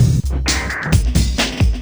drums02.wav